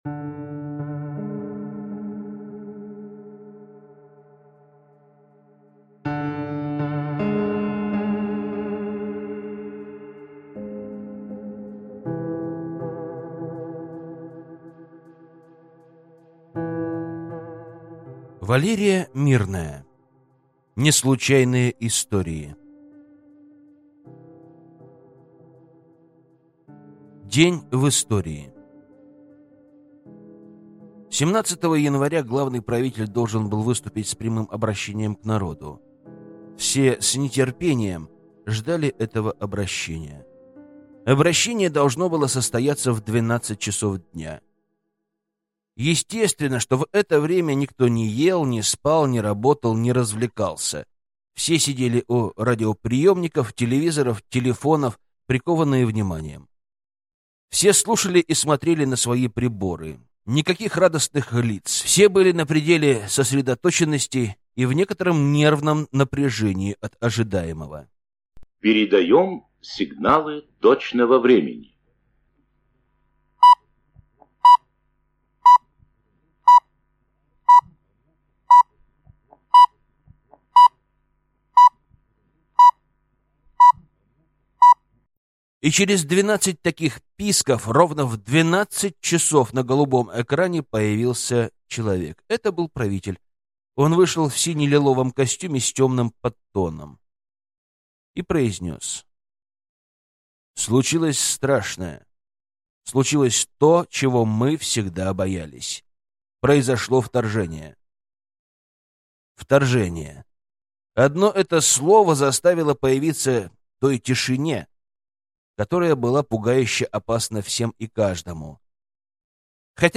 Аудиокнига Неслучайные Истории | Библиотека аудиокниг